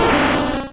Cri d'Ectoplasma dans Pokémon Rouge et Bleu.